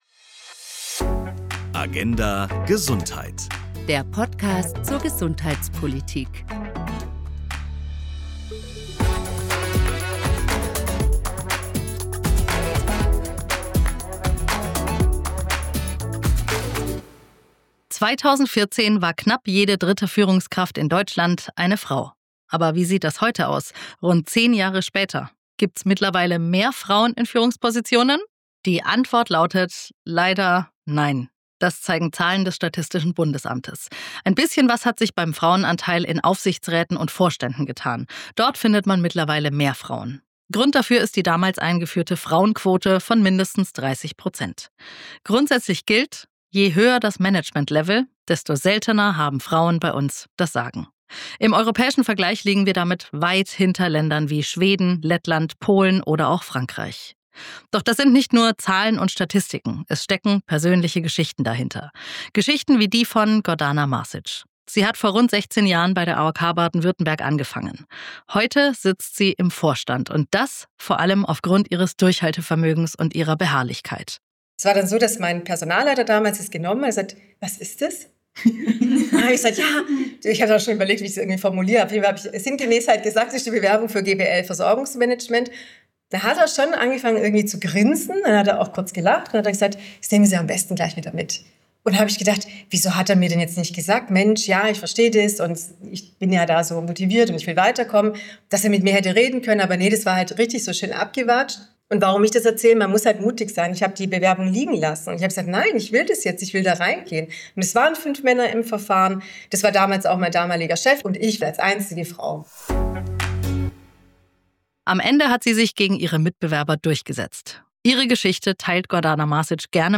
In dieser Folge kommen Initiator/-innen, Teilnehmerinnen und Trainerinnen zu Wort. Sie sprechen über Hürden und Aha-Momente auf dem Weg in Führung.